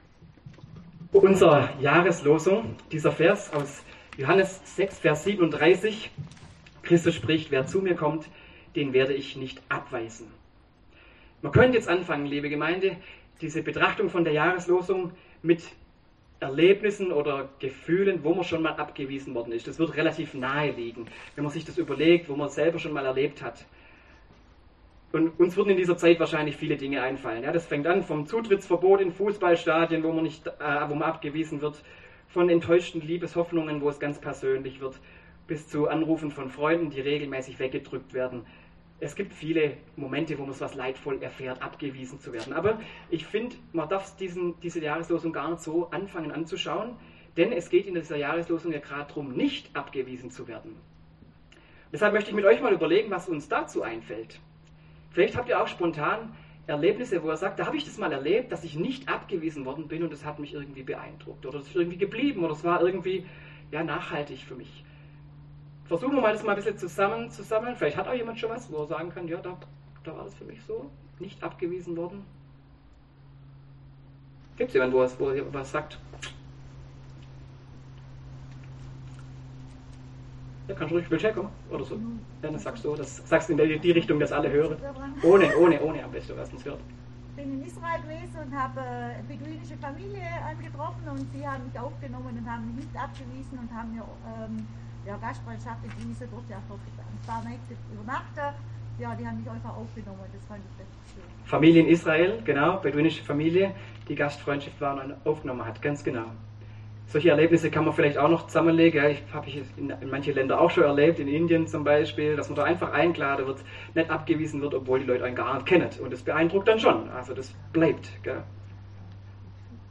Predigt
beim Neujahrsgottesdienst im Pfarrhof zur Jahreslosung 2022: